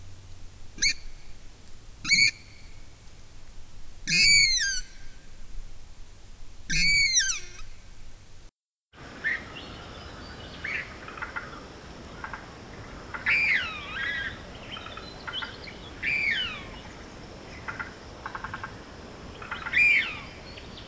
Purple Swamphen
purple-swamphen.mp3